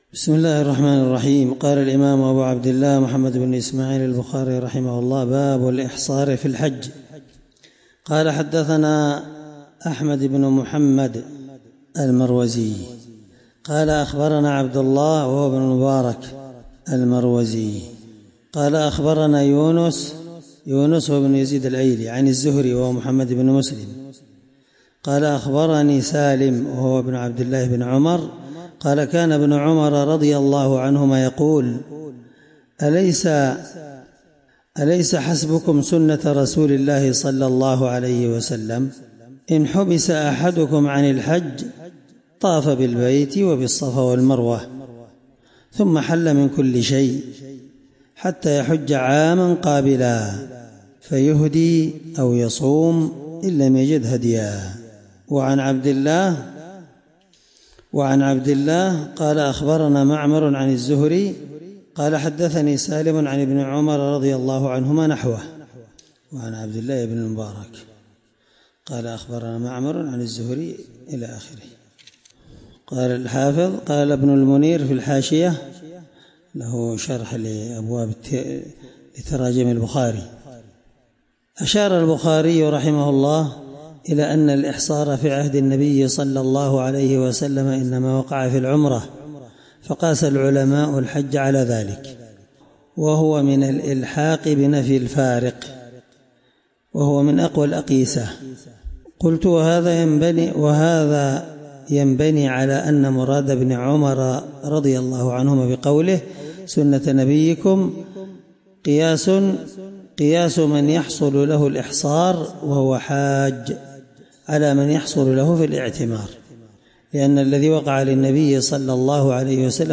الدرس 2من شرح كتاب المُحصر حديث رقم(1810 )من صحيح البخاري